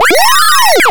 SpeedyPowerup.mp3